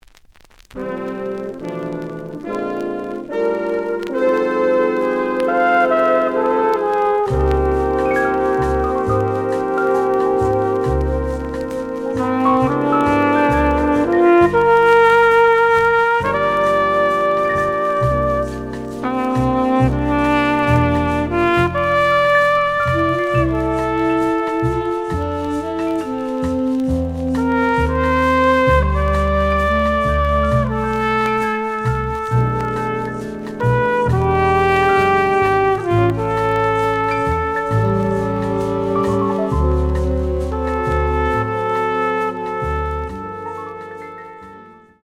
The audio sample is recorded from the actual item.
●Genre: Big Band